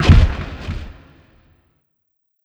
FX (Explosion).wav